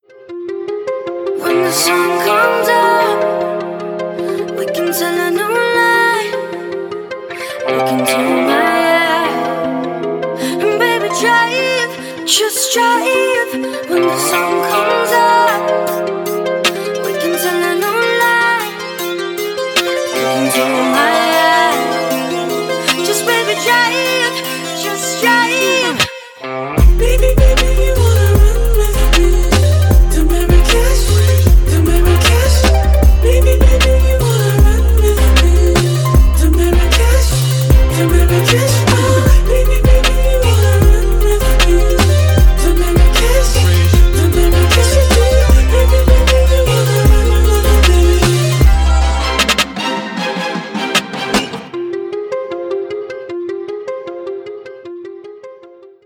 • Качество: 320, Stereo
красивые
грустные
dance
Electronic
Downtempo